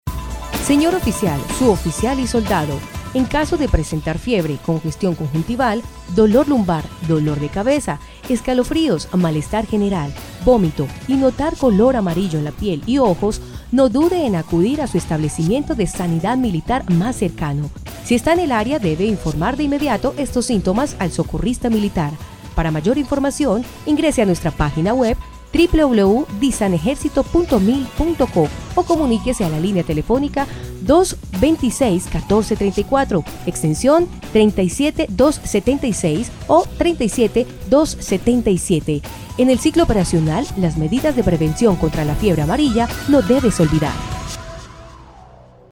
Cuña radial para personal Militar